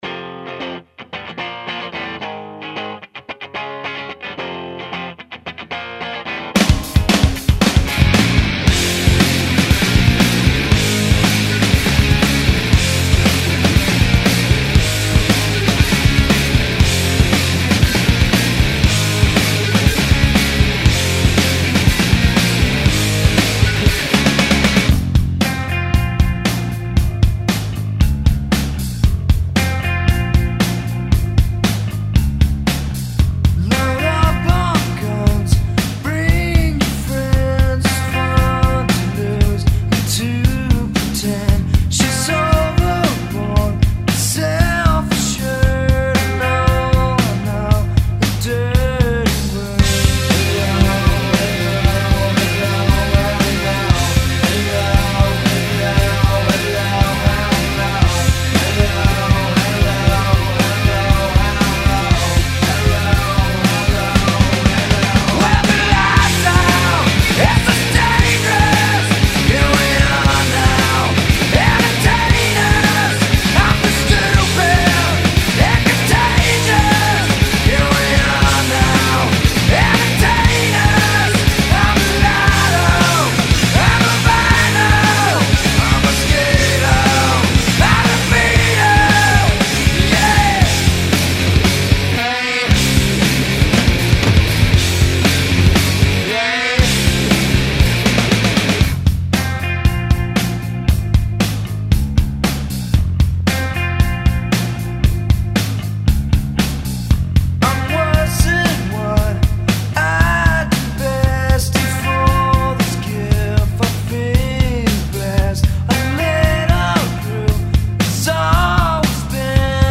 很摇滚哦